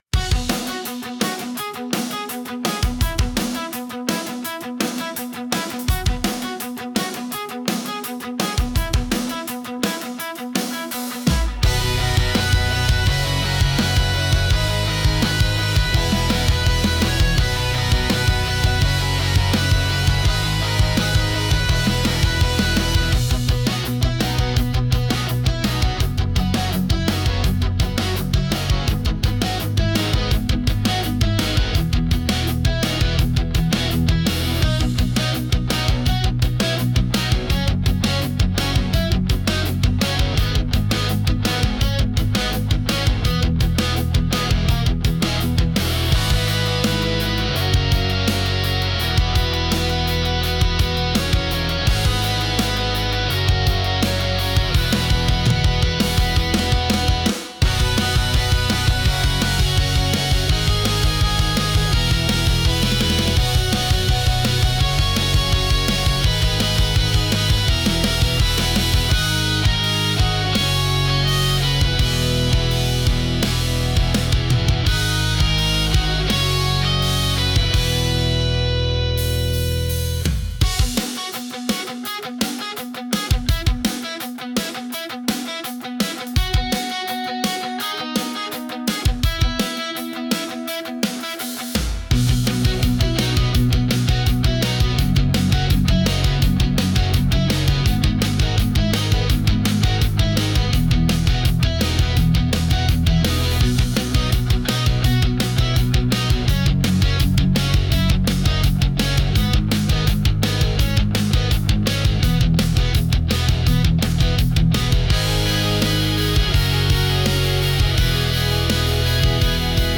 Genre: Pop Punk Mood: Energetic Editor's Choice